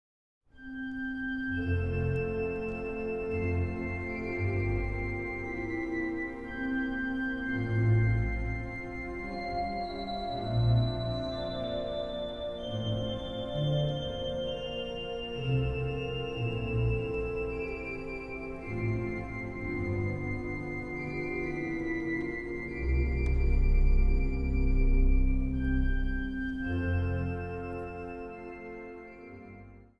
orgel.
Zang | Kinderkoor